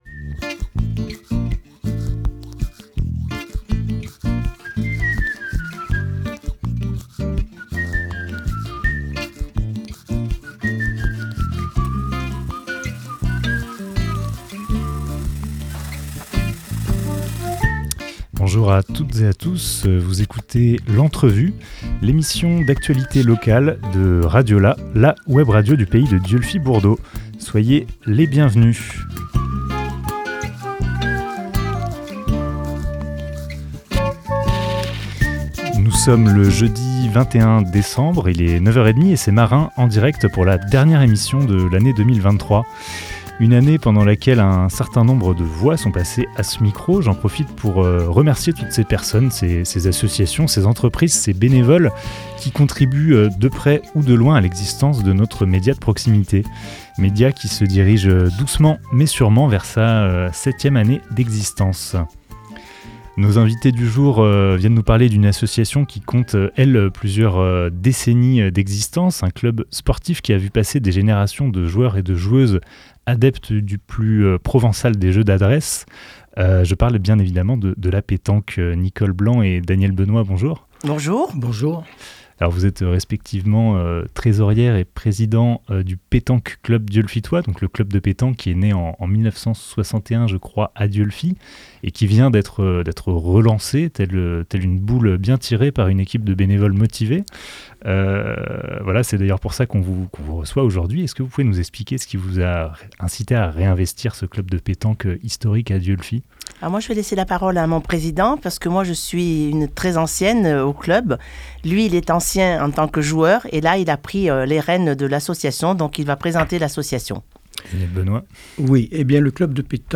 21 décembre 2023 14:23 | Interview